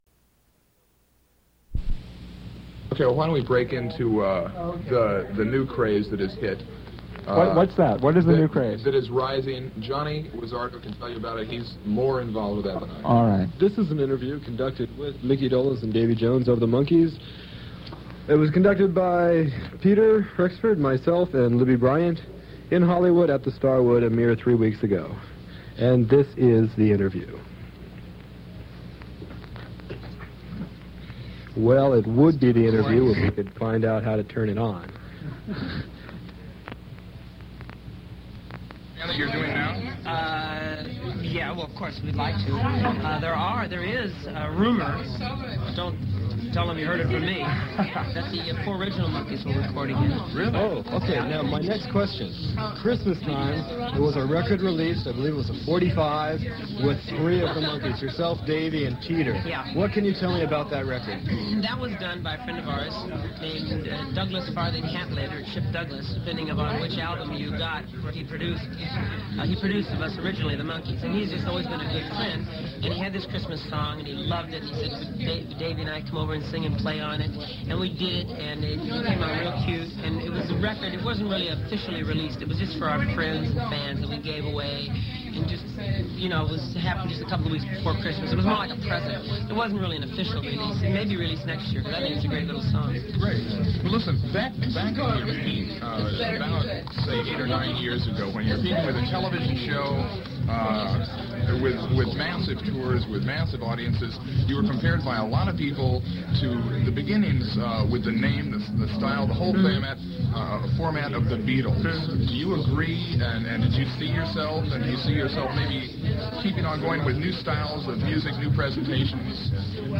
Davy Jones and Micky Dolenz 1977-7-3 starwood club 20 Radio Interview at the Starwood